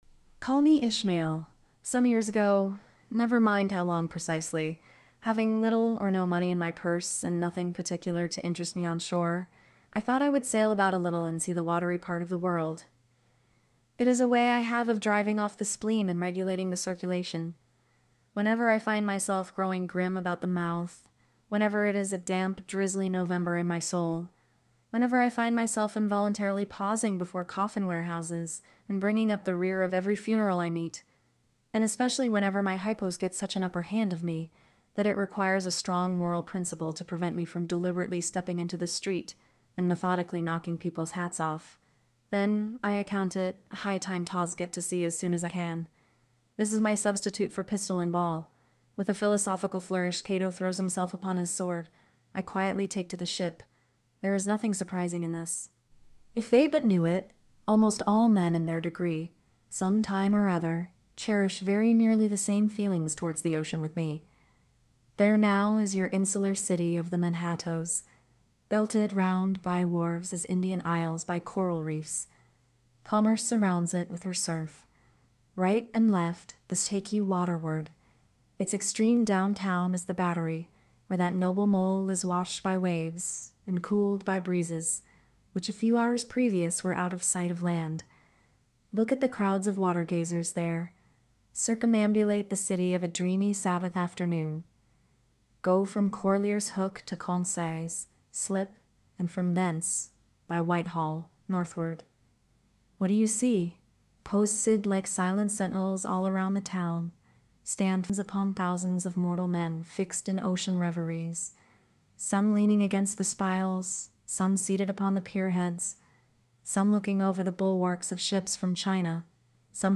Orpheus TTS - Demo Files